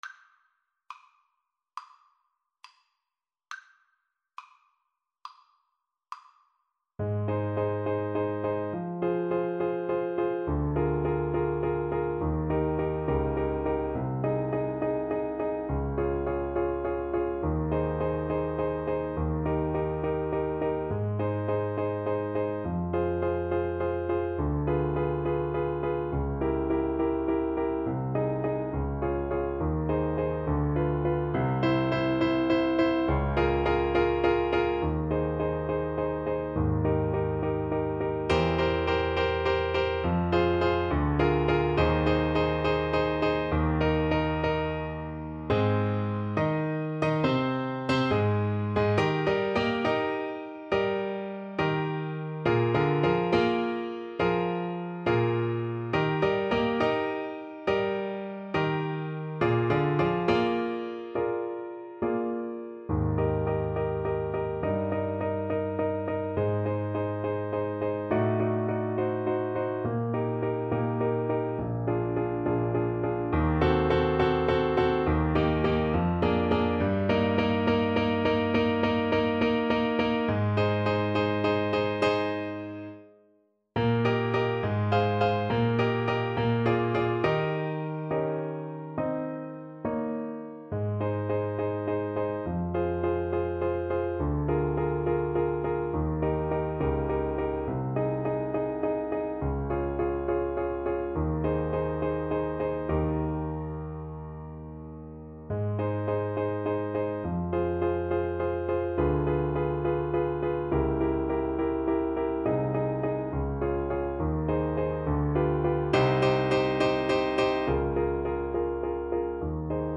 Ab major (Sounding Pitch) Bb major (Clarinet in Bb) (View more Ab major Music for Clarinet )
4/4 (View more 4/4 Music)
Classical (View more Classical Clarinet Music)